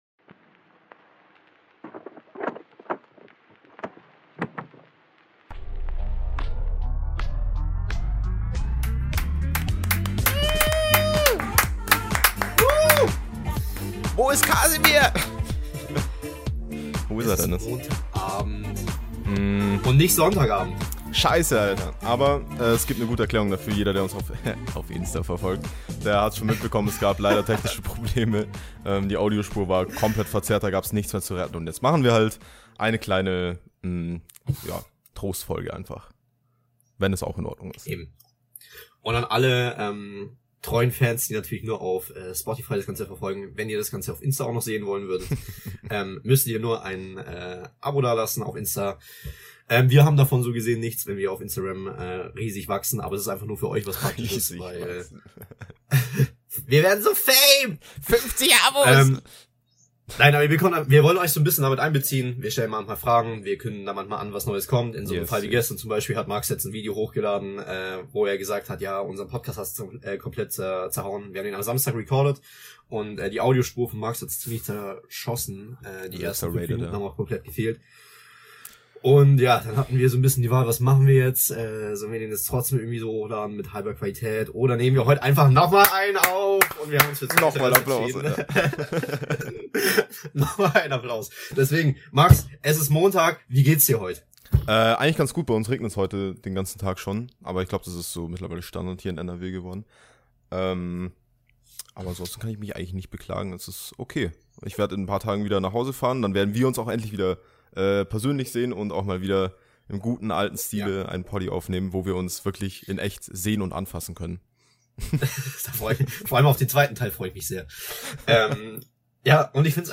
Leider gab es unlösbare Audioprobleme weshalb wir uns dazu entschieden haben, die letzte Folge nochmal zu rekapitulieren und in ein kleines Trostfölglein zu verpacken!